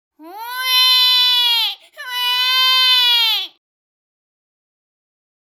Le twang est utile pour faciliter plusieurs sons, en particulier les sons hauts et forts (belting).
Par exemple, pour trouver le twang… Imiter un enfant qui pleure: